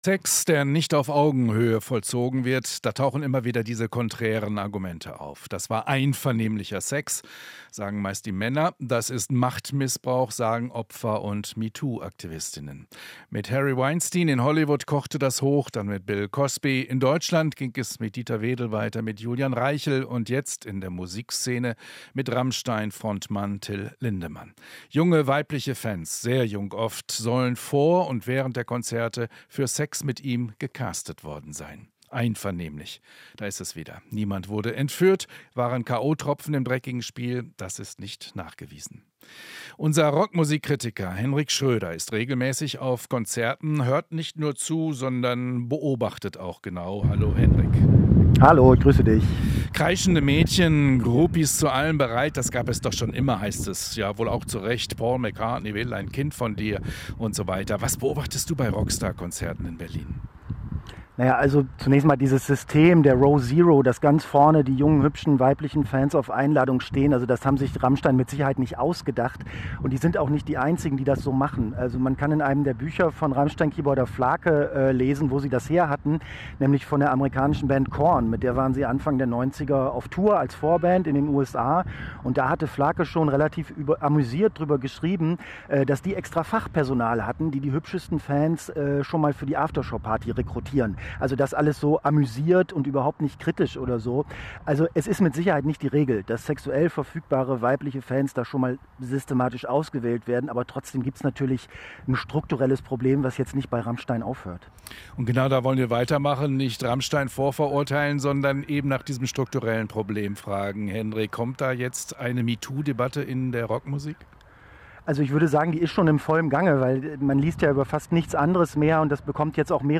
Inforadio Nachrichten, 06.06.2023, 17:00 Uhr - 06.06.2023